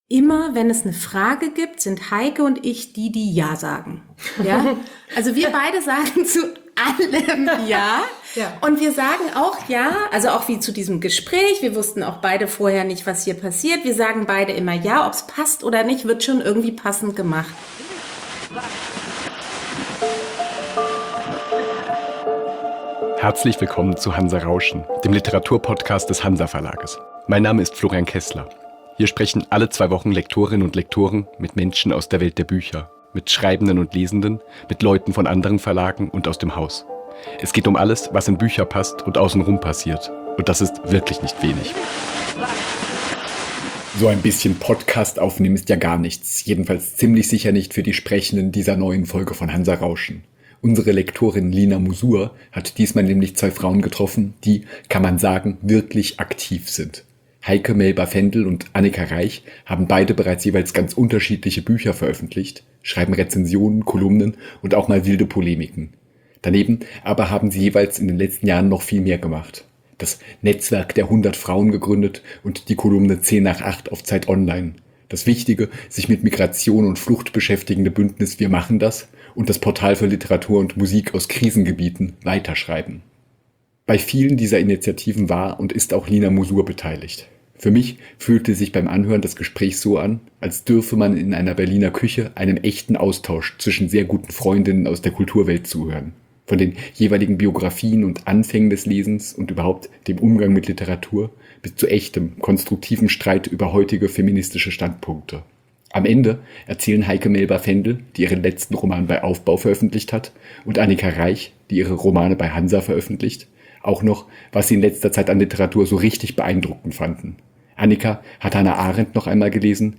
In der siebten Episode von »Hanser Rauschen« sind wir zu Gast bei drei Freundinnen in Berlin: